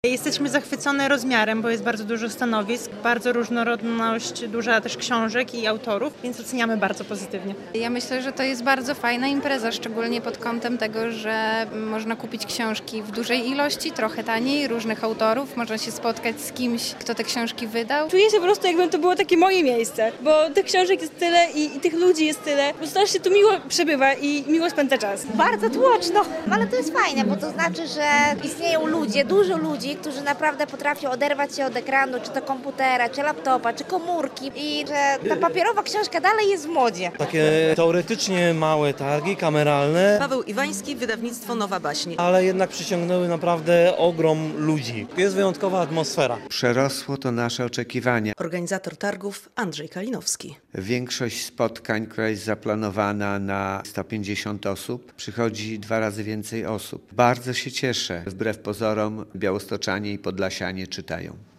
Na Stadionie Miejskim w Białymstoku niedziela (23.04) była trzecim, finałowym dniem Targów Książki.
Ostatni dzień 10. Targów Książki w Białymstoku- relacja